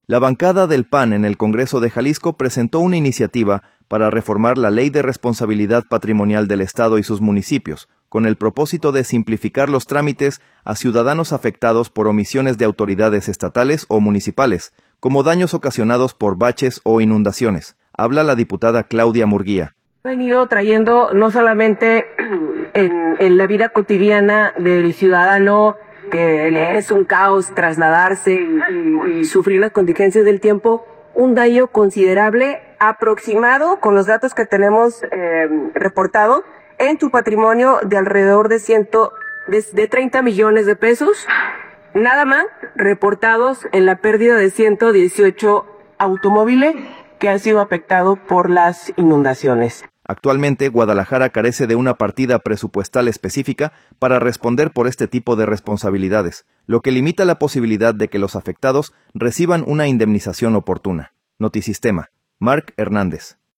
Habla la diputada, Claudia Murguía: